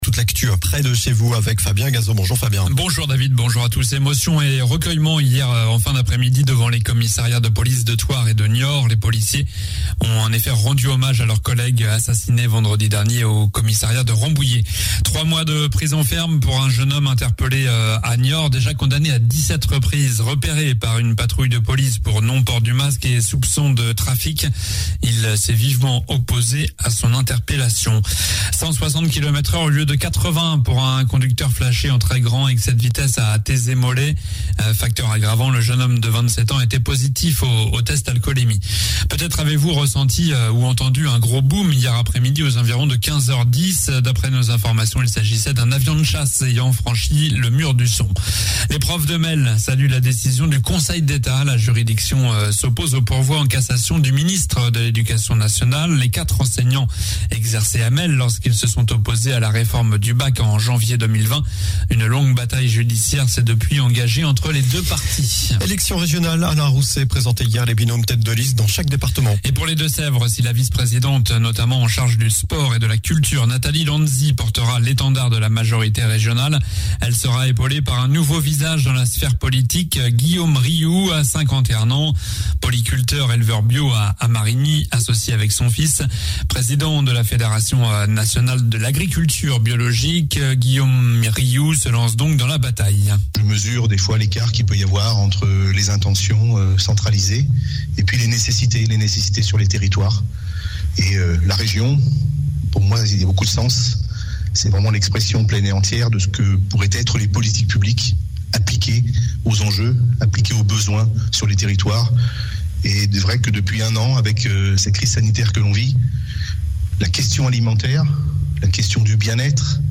JOURNAL DU MARDI 27 AVRIL (MATIN)